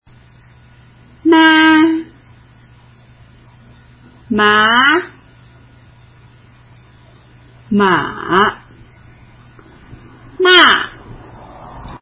第１声 少し高めの音から平らにのばす
第２声 自然な高さから急激にあげる
第３声 低く抑えゆっくりと上げる
第４声 少し高めの音から急激にさげる
四声多くの本で必ず出てくる｢ma｣で説明します。